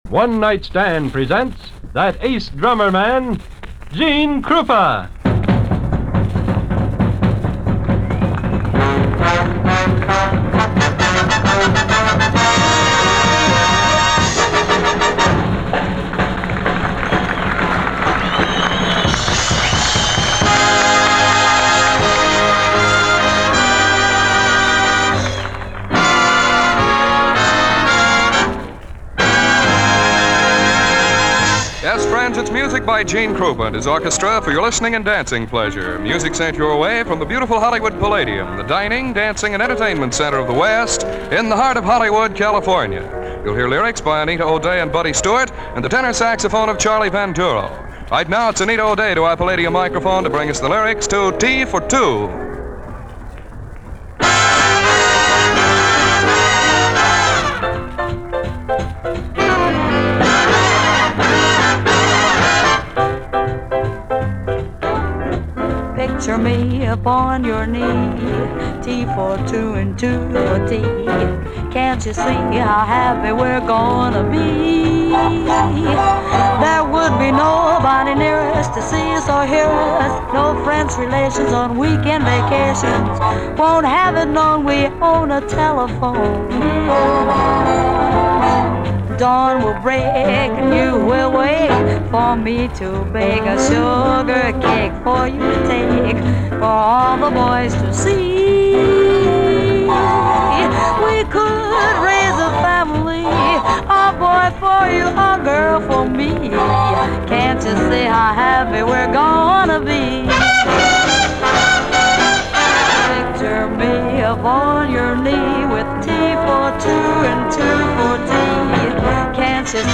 live at The Hollywood Palladium
on saxophone, recorded at The Hollywood Palladium
unique phrasing and pure tone